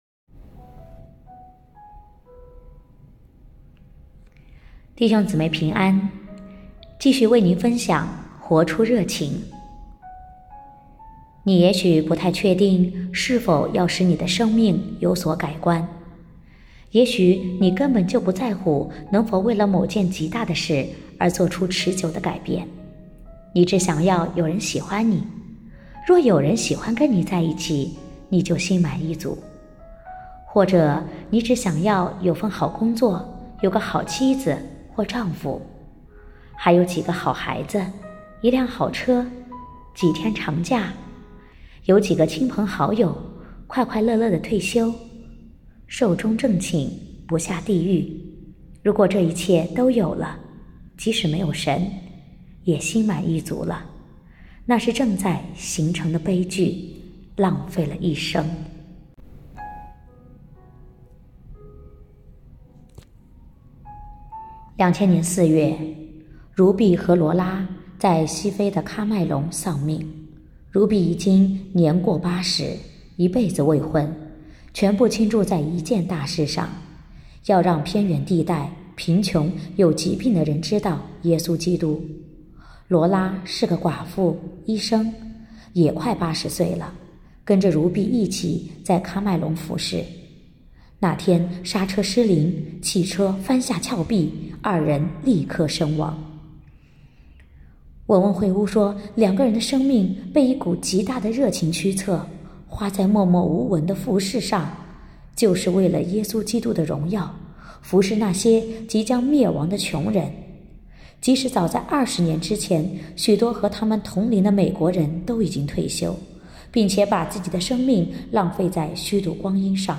2023年8月17日 “伴你读书”，正在为您朗读：《活出热情》 https